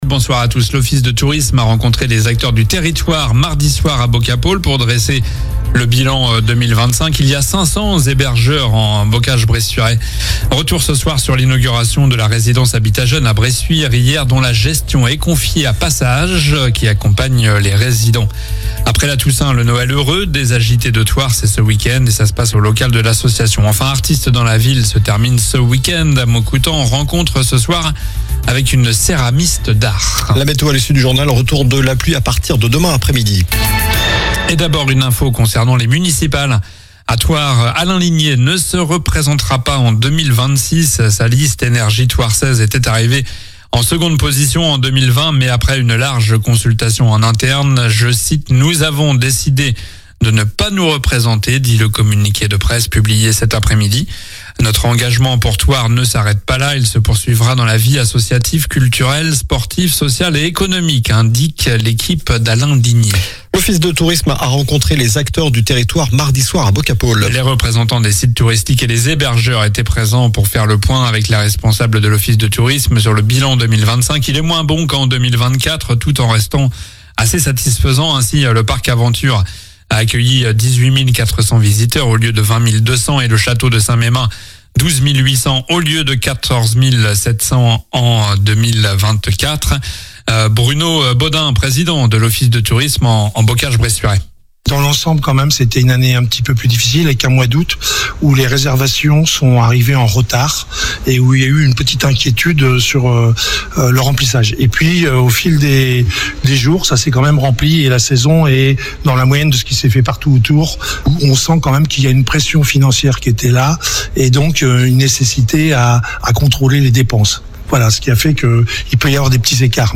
COLLINES LA RADIO : Réécoutez les flash infos et les différentes chroniques de votre radio⬦